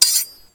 sword.6.ogg